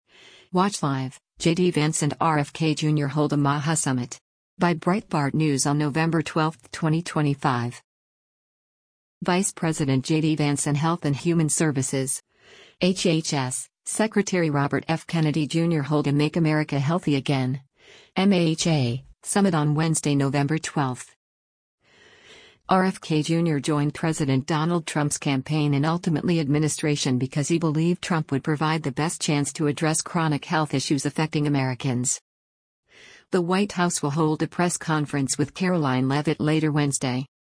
Vice President JD Vance and Health and Human Services (HHS) Secretary Robert F. Kennedy Jr. hold a Make America Healthy Again (MAHA) Summit on Wednesday, November 12.